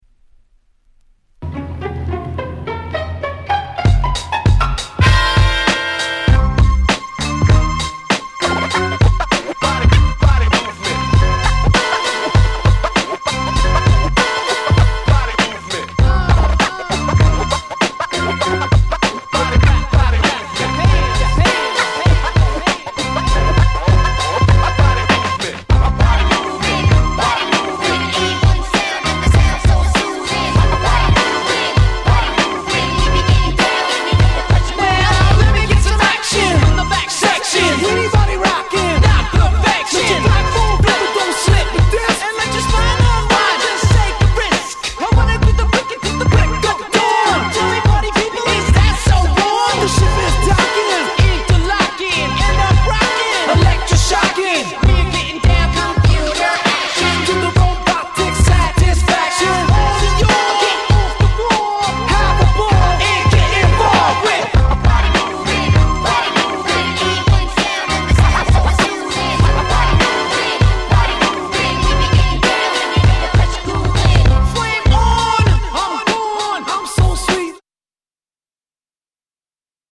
98' Hip Hop Classic !!